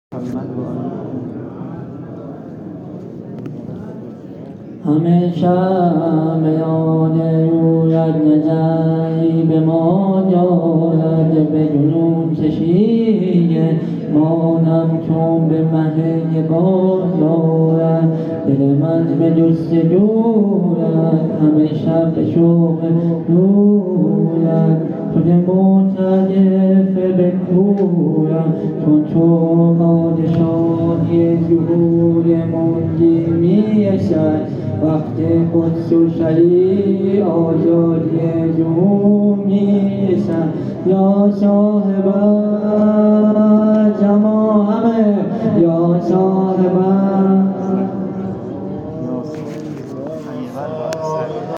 مسجد حاج حسن خرقانی تهران